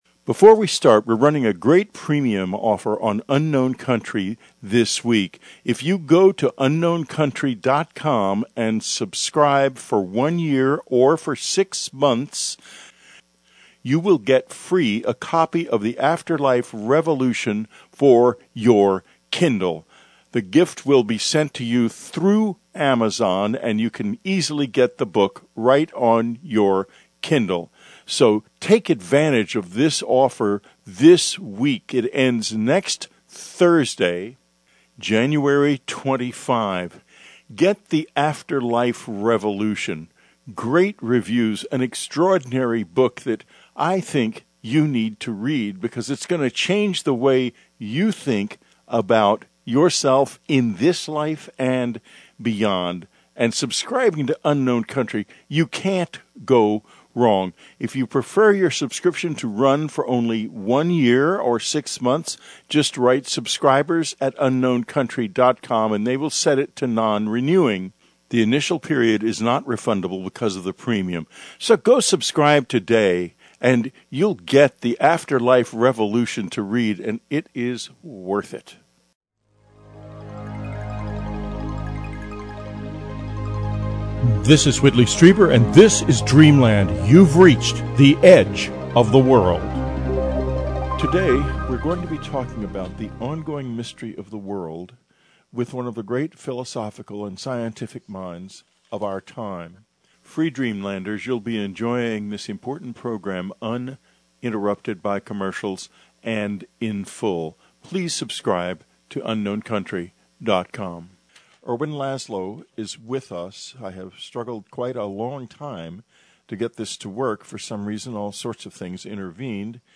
Whitley Strieber’s recent interview with Ervin Laszlo. What is consciousness and why does it matter whether or not we know? (a little glitch in the beginning) .